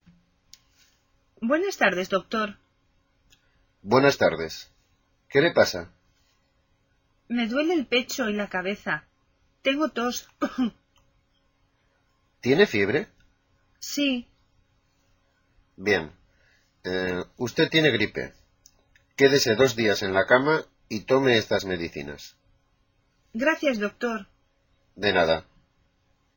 Escucha la conversación del doctor con María (la paciente) y contesta verdadero o falso a las afirmaciones siguientes.